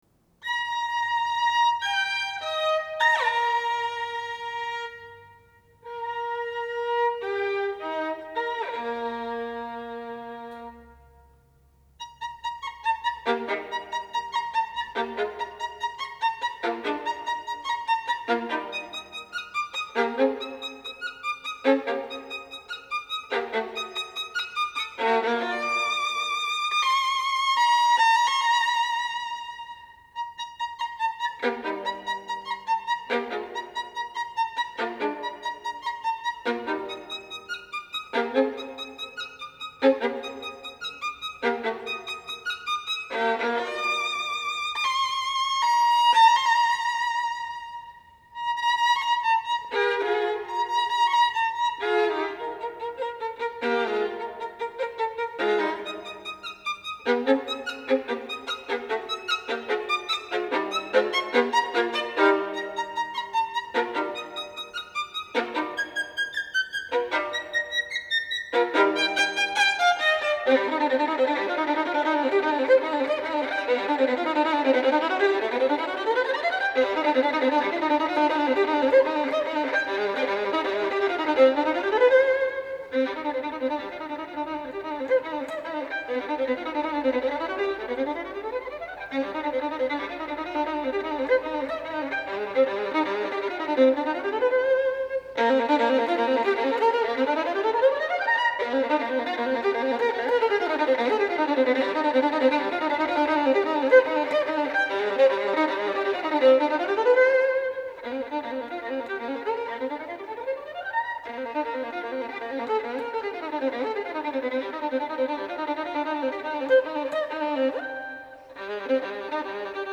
для скрипки соло